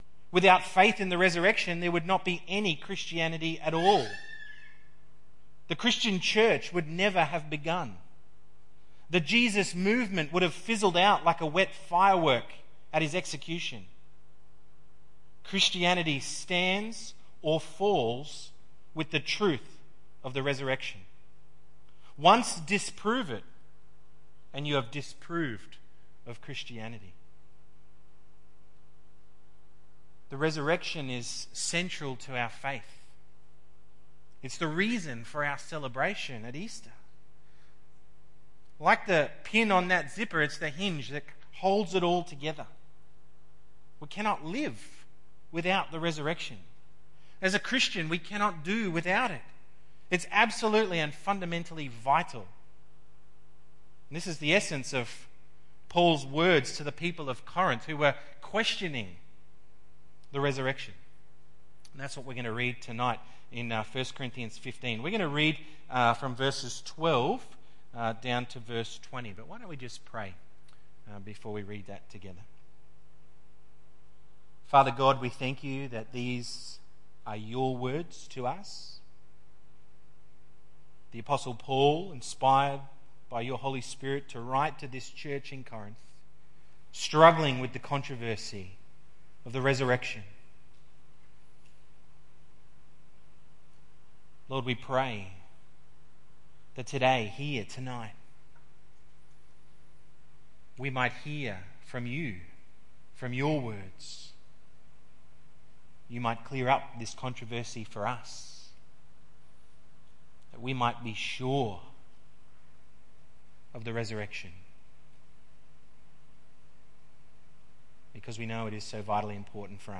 why the resurrection of Jesus acts as the hinge of gospel hope. 1 Corinthians 15:12-20 Tagged with Sunday Evening